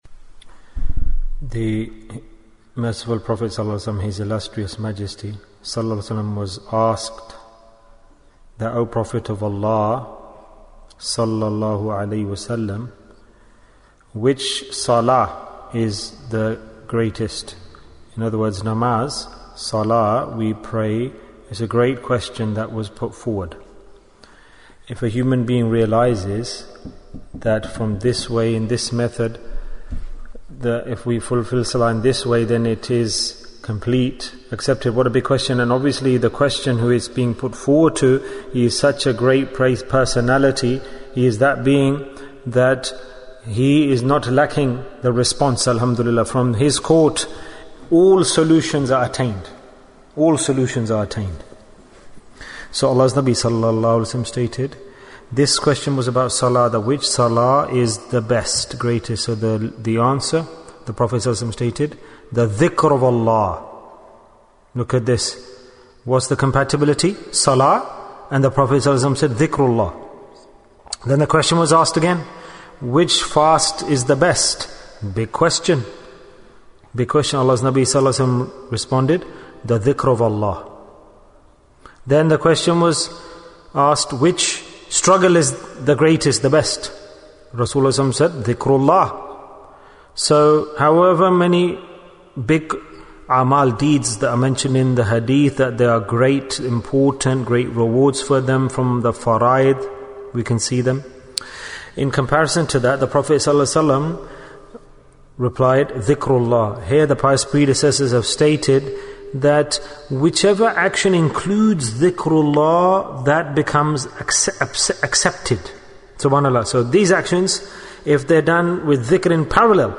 The Status of Dhakireen Bayan, 19 minutes3rd December, 2020